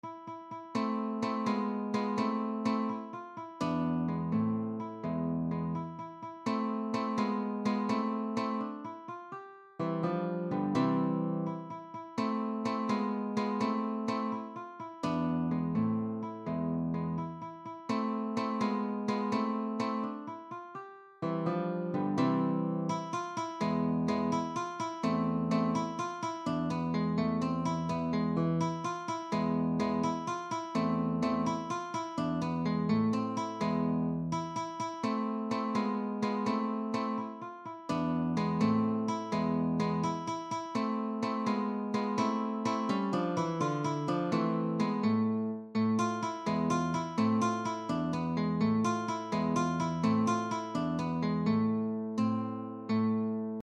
SICILIANA
—>È una danza lenta in 6/8 o in 12/8 caratterizzata da ritmi